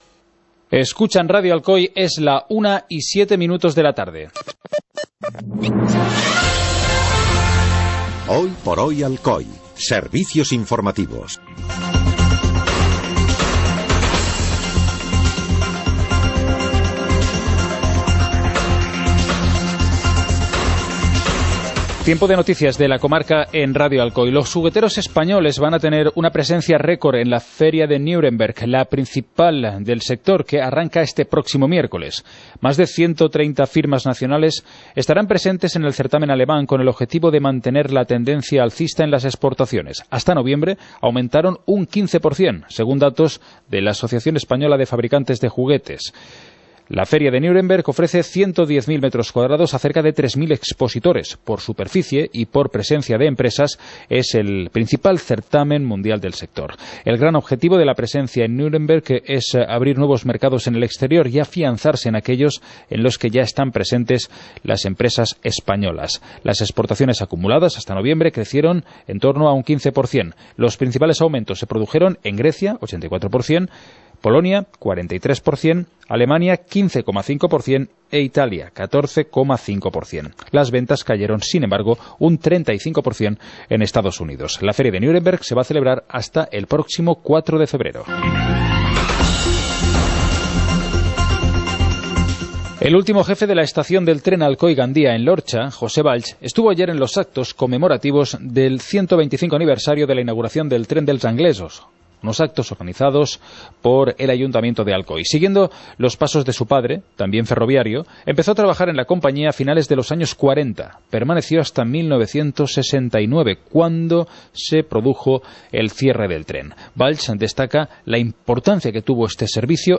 Informativo comarcal - viernes, 26 de enero de 2018